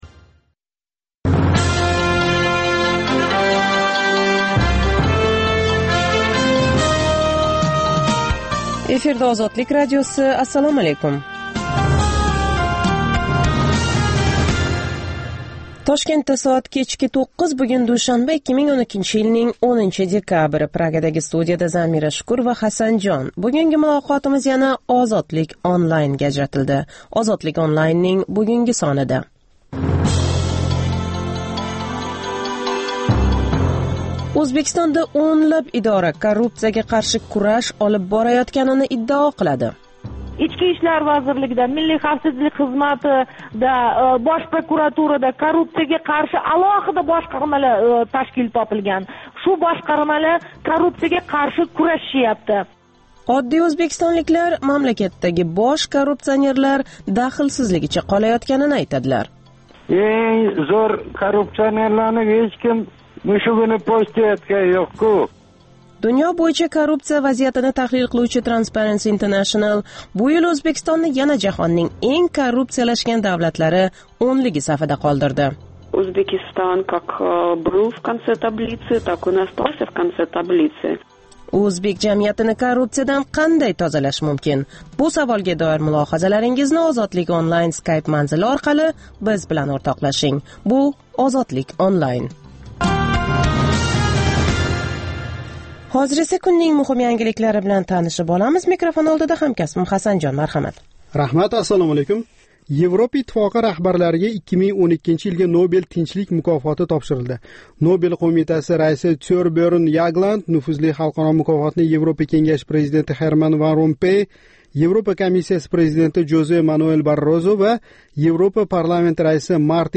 “Ozodlik Online” - Интернетдаги энг замонавий медиа платформаларни битта тугал аудио дастурга бирлаштирган Озодликнинг жонли интерактив лойиҳаси.